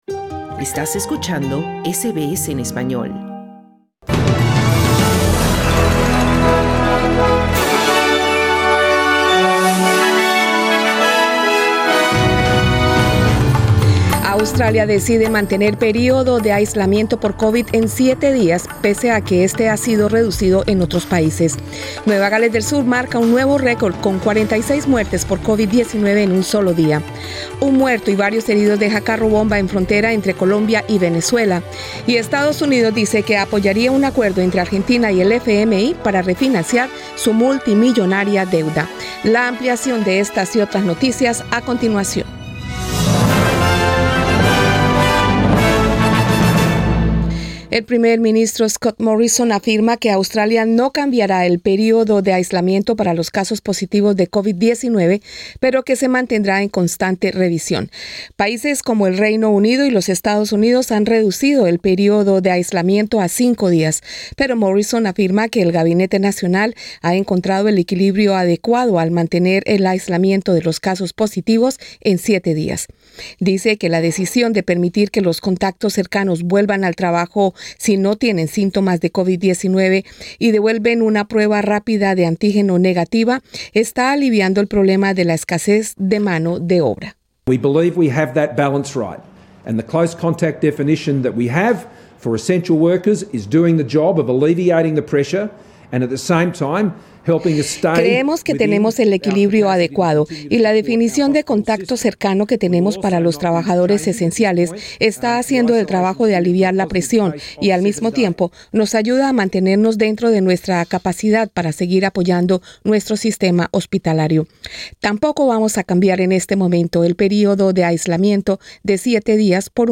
Noticias SBS Spanish | 21 enero 2022